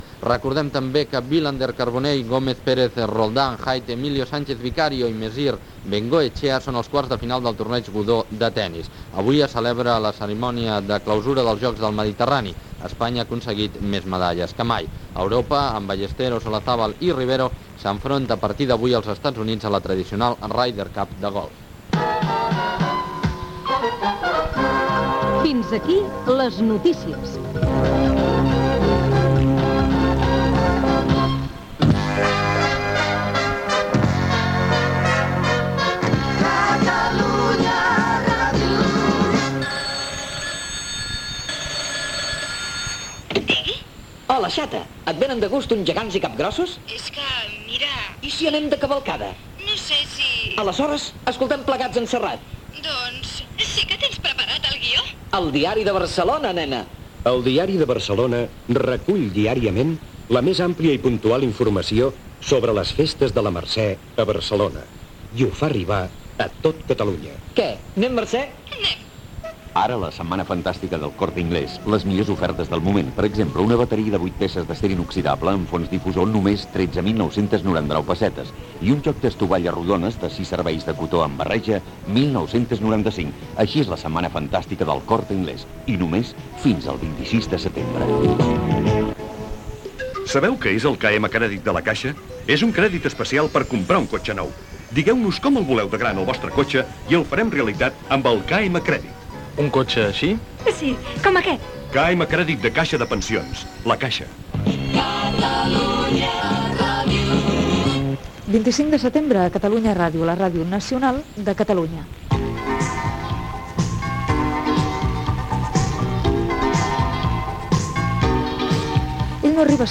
Final del butlletí de notícies, amb els esports, indicatiu de la ràdio, publicitat, indicatiu, inici del següent programa
FM